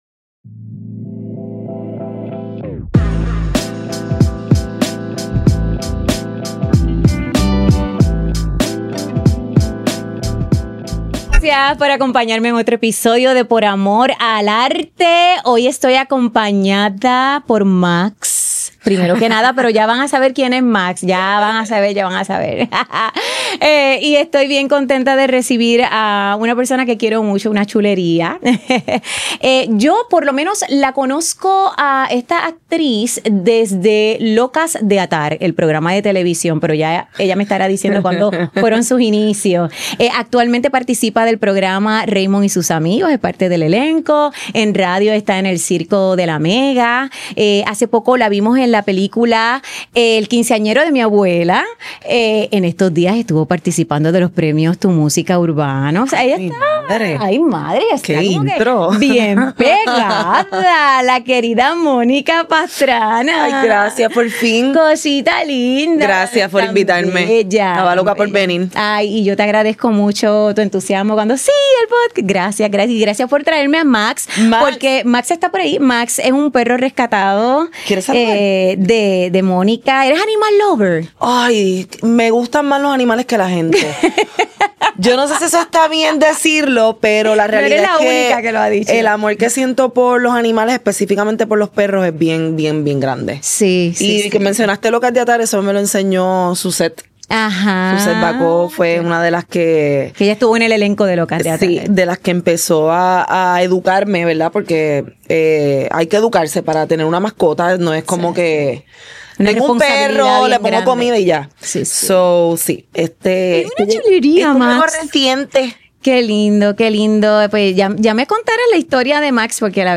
Grabado en GW-Cinco Studio